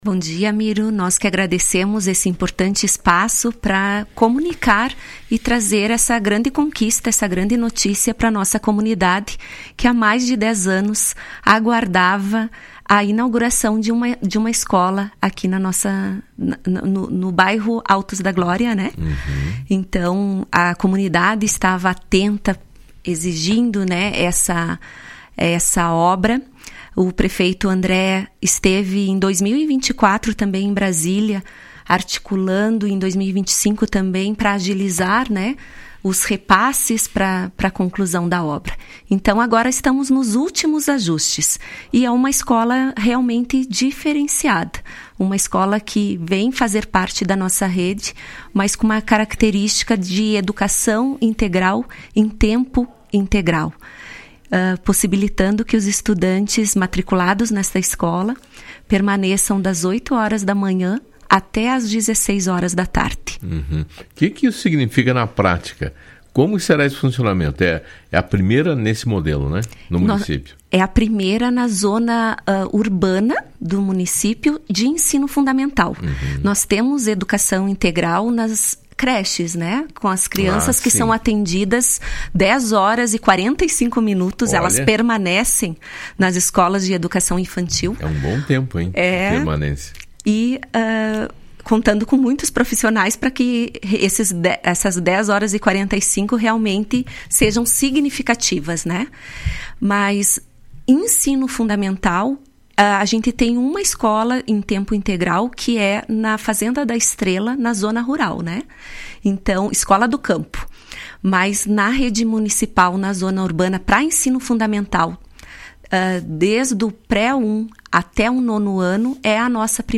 A Secretária Municipal de Educação, Adriana Ferreira Boeira participou do programa Fala Cidade na manhã desta quinta-feira e falou da importância que esse novo estabelecimento terá para Vacaria.